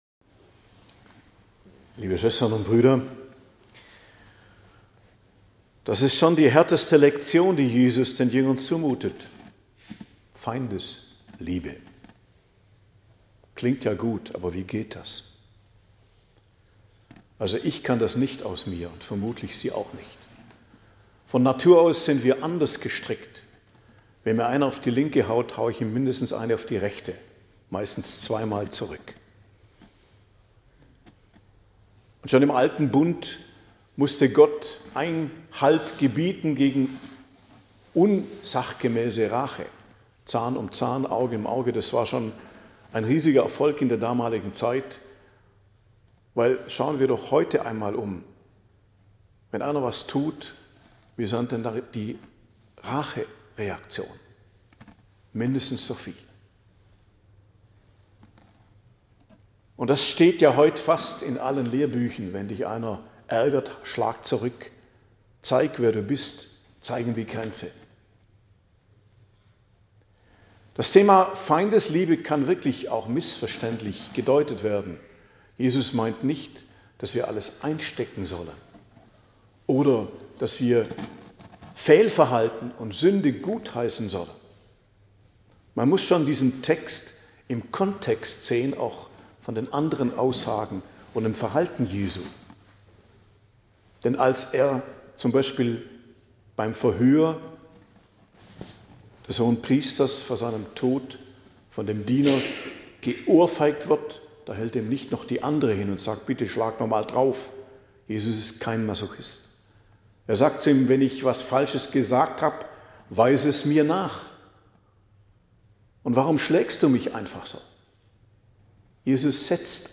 Predigt zum 7. Sonntag i.J. am 23.02.2025 ~ Geistliches Zentrum Kloster Heiligkreuztal Podcast